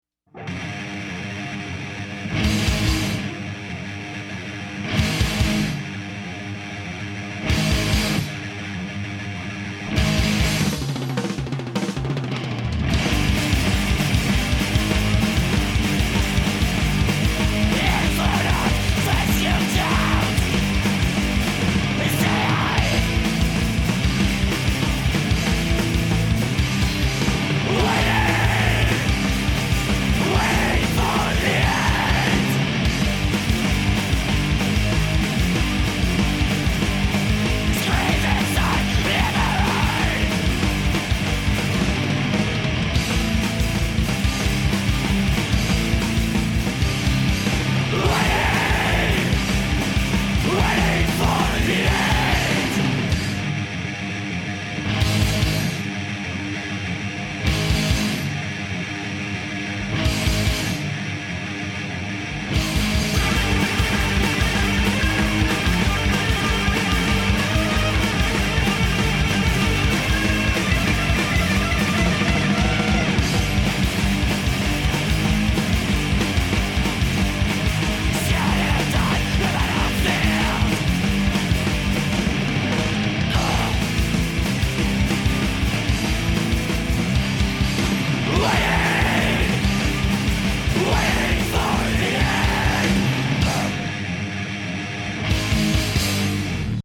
guitar, vocals
bass, vocals
drums